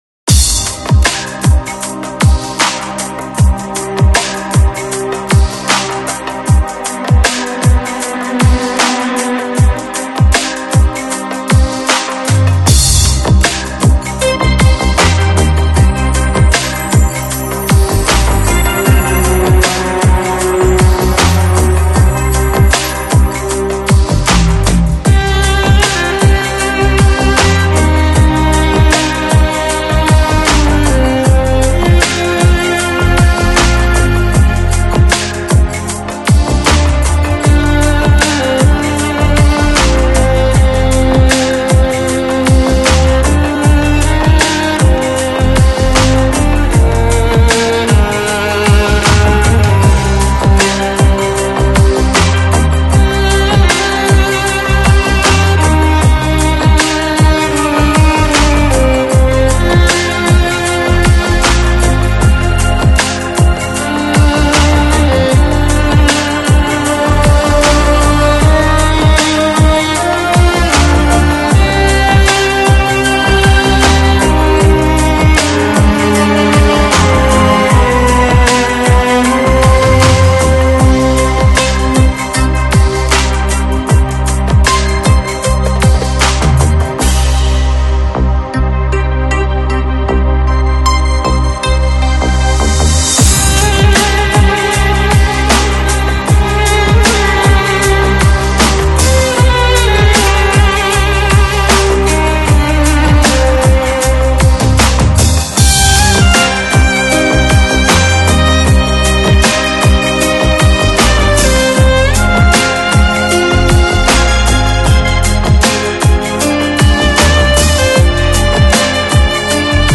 Жанр: Instrumental, Neo-Classical, New Age
一个由可爱的女孩组成的器乐乐队，以不同的风格和方向演奏器乐音乐。
小提琴和键盘、鼓、打击乐、吉他和手风琴、白俄罗斯琴和印度锡塔尔琴、中国胡卢西——乐器都是乐队在舞台上表演的音乐角色。